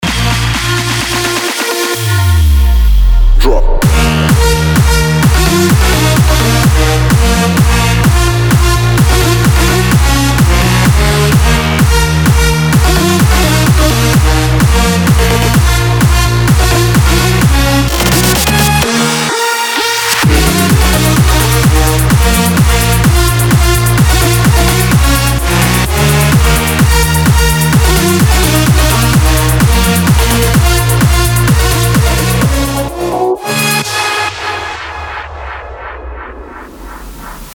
• Качество: 320, Stereo
громкие
dance
progressive house
electro house
Музыка от российского диджея